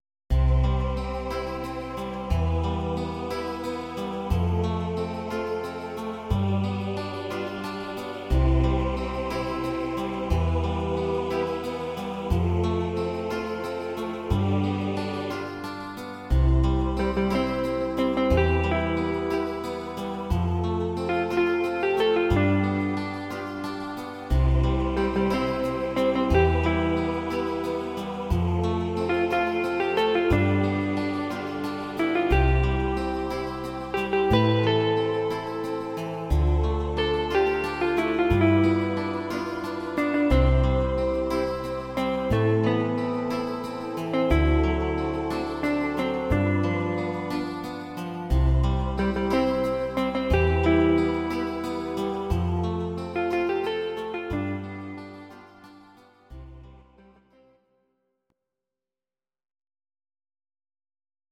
Please note: no vocals and no karaoke included.
Your-Mix: Jazz/Big Band (731)